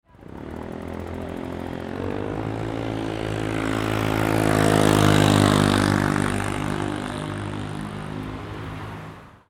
Urban Motorcycle Drive By Sound Effect
Realistic sound effect of a motorcycle passing by on a city street.
Genres: Sound Effects
Urban-motorcycle-drive-by-sound-effect.mp3